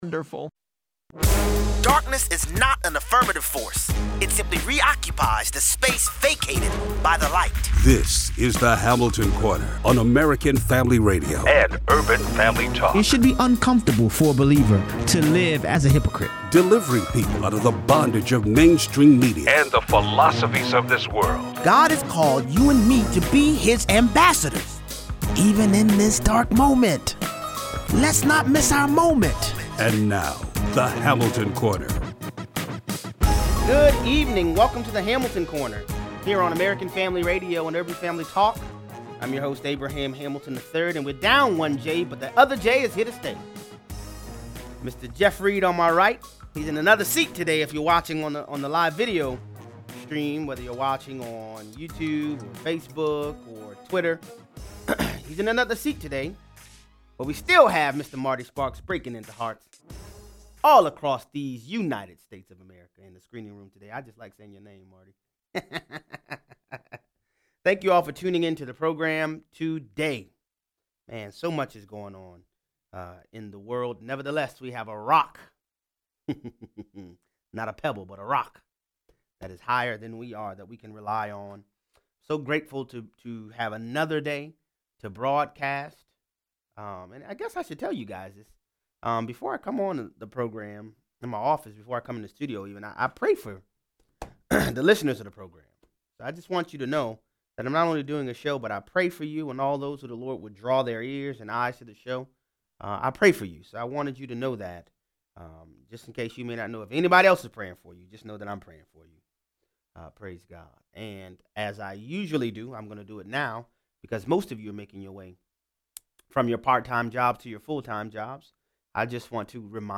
STEM School in Highlands Ranch, CO isn’t for the gun control foolishness. 0:38 - 0:55: According to ICE officials, 1,000 illegal immigrants are being dropped off in American border towns daily. Callers weigh in.